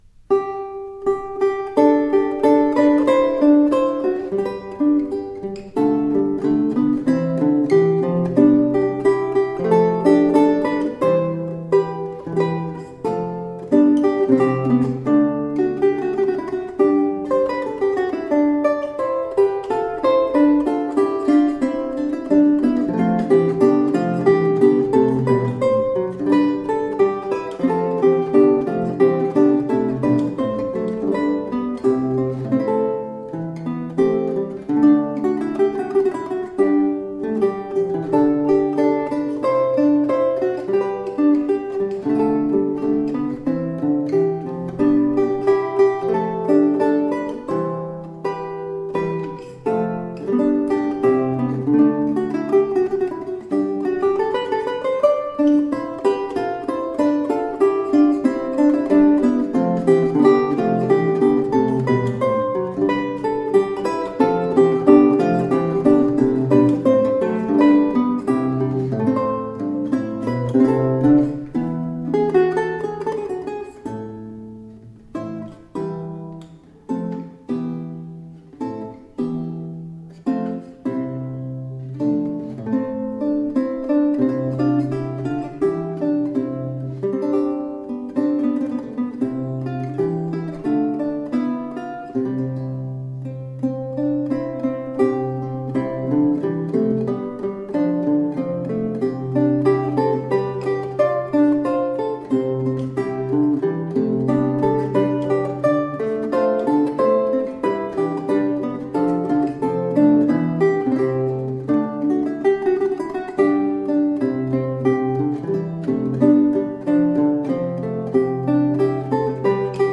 Renaissance lute.
Recorded 15-16 December 1998 in Wiltshire, UK
Classical, Renaissance, Instrumental